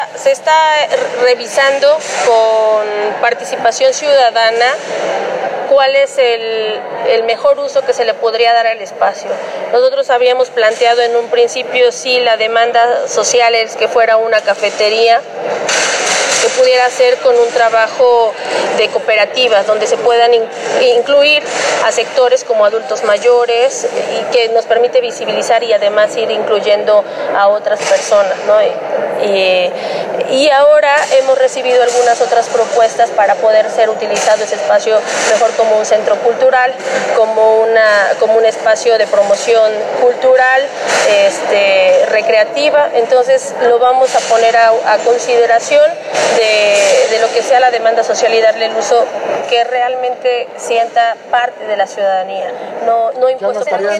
En entrevista, la edil especificó que si llegara a efectuarse la confirmación de una cooperativa, se contemplará un proceso de licitación a fin de garantizar que las nuevas personas que estén a cargo del lugar cumplan con las características que el ayuntamiento de Puebla disponga como es la contratación de adultos mayores, y que exista la promoción de la lectura.